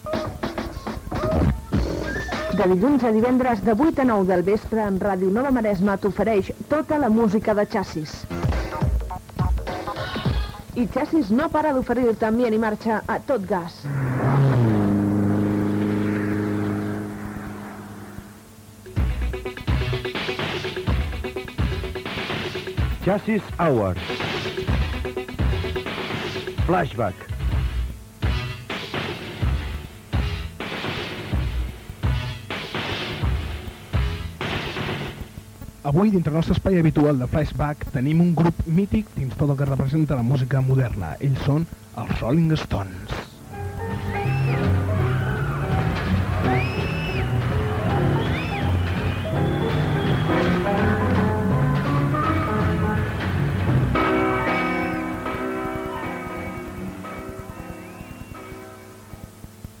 Programa musical esponsoritzat per la discoteca Chasis de Mataró.
Musical